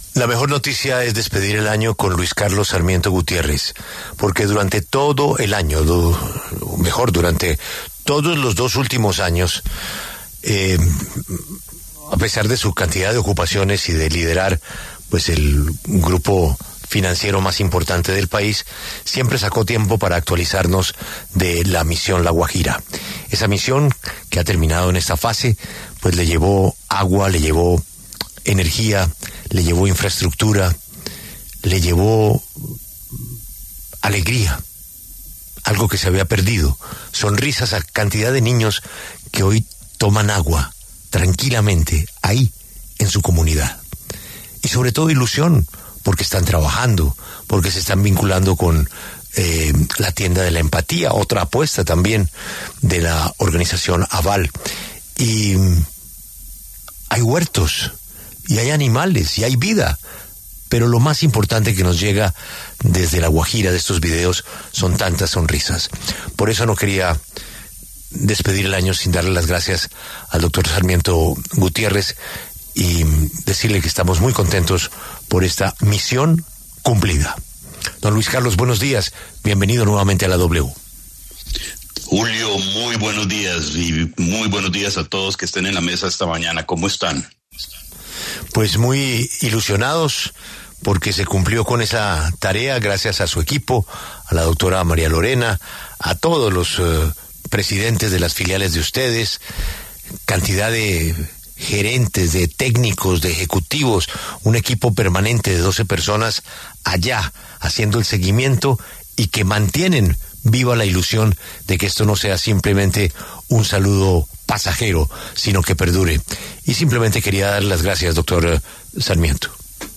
Luis Carlos Sarmiento Gutiérrez, presidente del Grupo Aval, pasó por los micrófonos de La W, con Julio Sánchez Cristo, para hacer un balance del año para su grupo empresarial, pero también para anunciar su apoyo a Vamos Pa’Lante, una campaña que apoya a jóvenes estudiantes colombianos para que continúen con sus carreras universitarias en diferentes instituciones de educación superior del país.